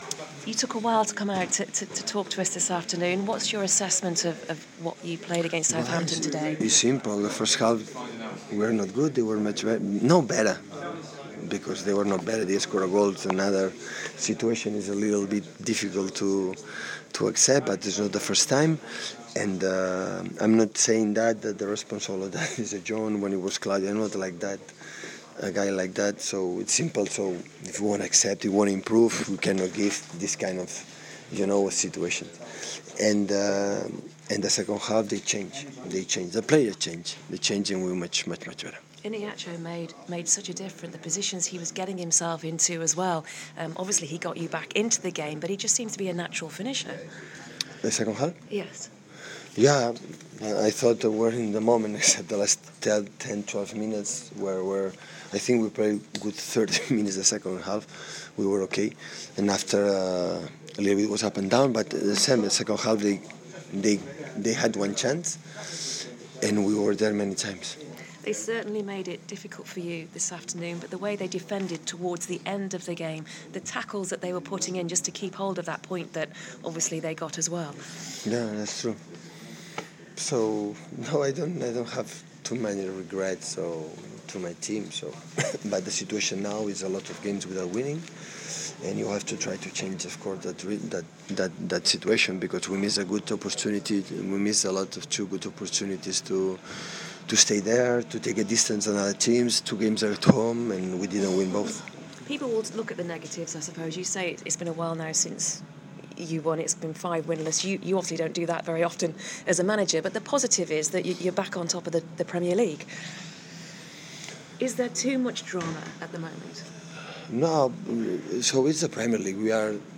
Manchester City manager speaks to the press following a disappointing 1-1 draw at home to Southampton.